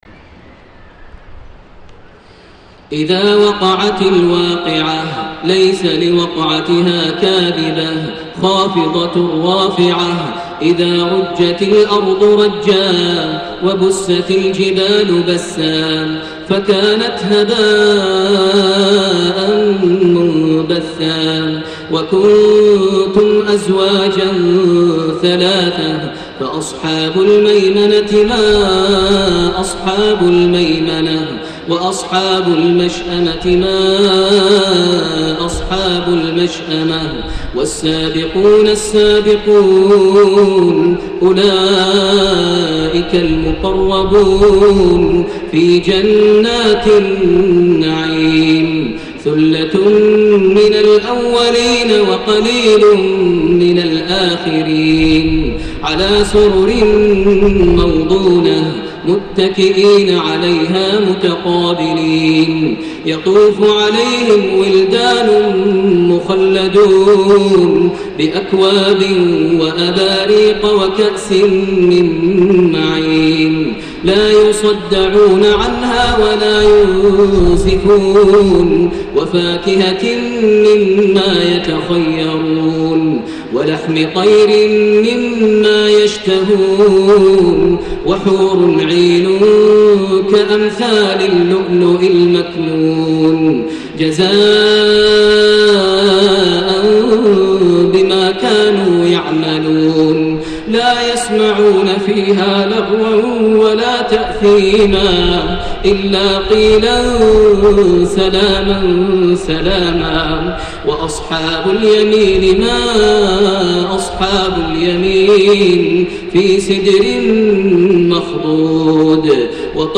صلاة الخسوف ١٤ محرم ١٤٣١هـ سورة الواقعة > صلاة الخسوف > المزيد - تلاوات ماهر المعيقلي